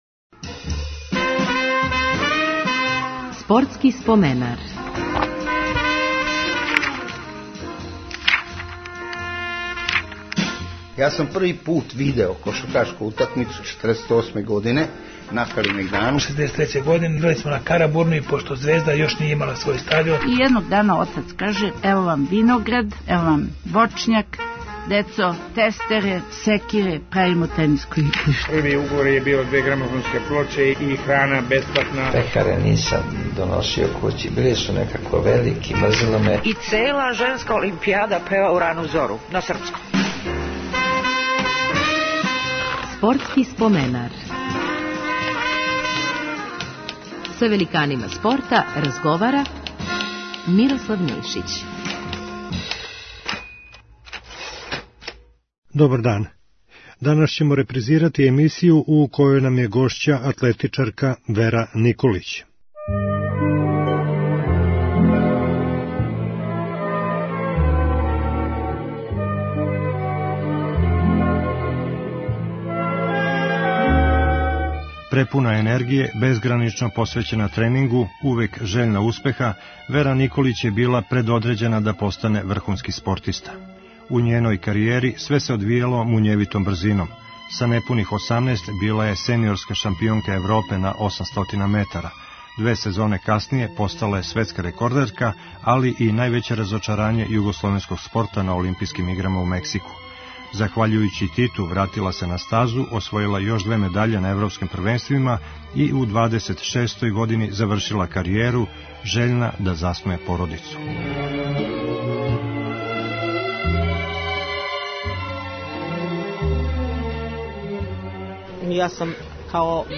Репризирамо емисију у којој нам је гошћа атлетичарка Вера Николић. Пре пола века, у лето 1968. је у Лондону оборила светски рекорд на 800 метара, а убрзо у Будимпешти постала европска шампионка.